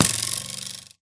bowhit4